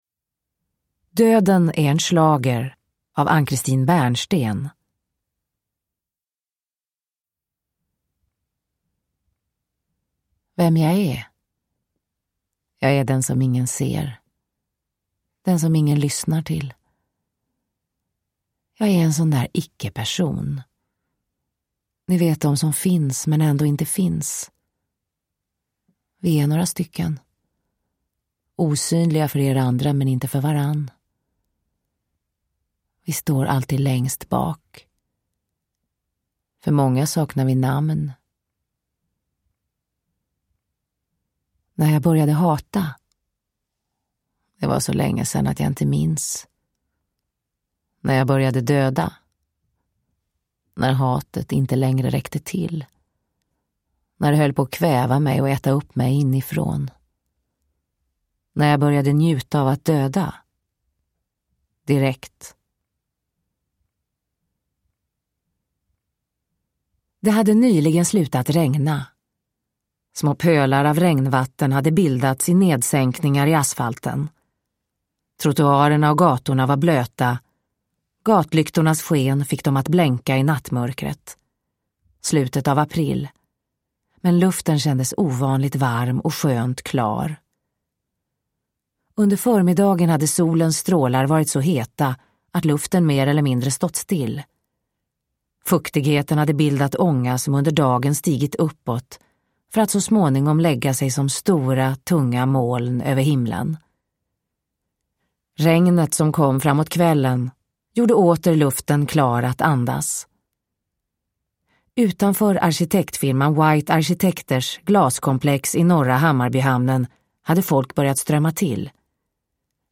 Döden är en schlager – Ljudbok – Laddas ner
Uppläsare: Marie Richardson